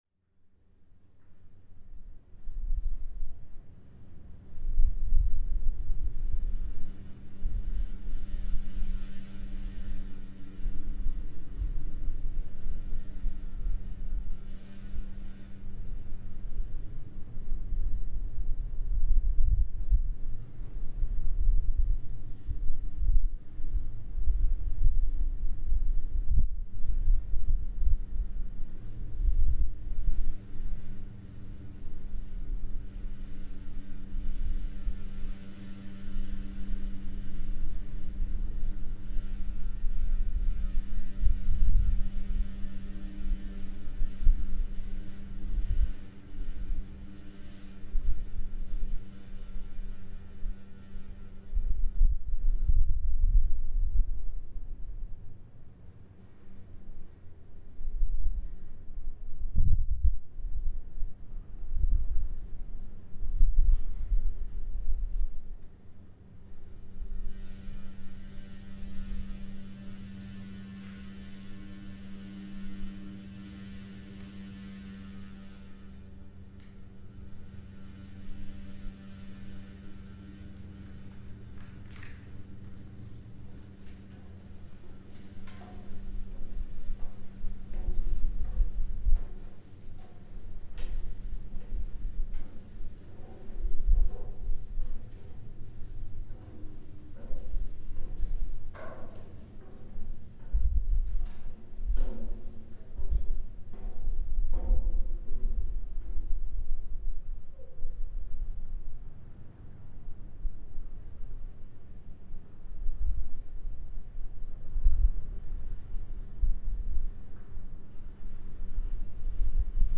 An iron staircase in the wind
The unique sound of an iron staircase being oscillated by strong winds in the Cooper's Island Nature Reserve, St. David, Bermuda.